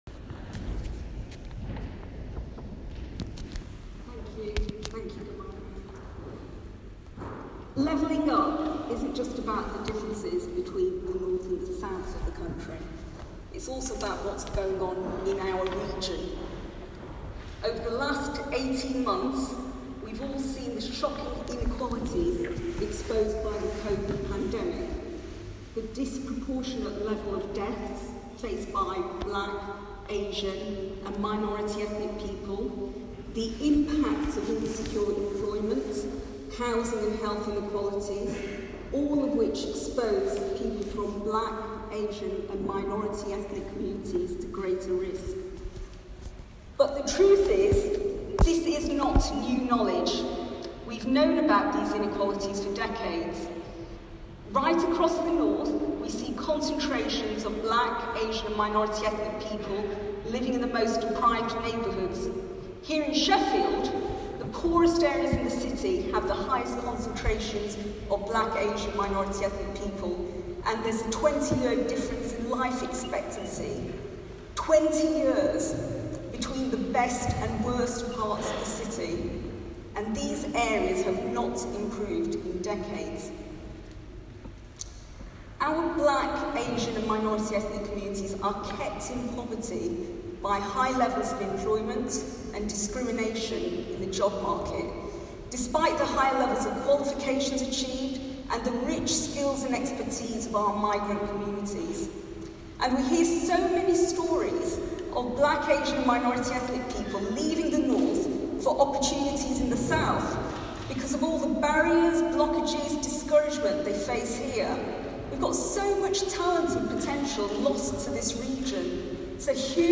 Speaking at The Great Northern Conference in October 2021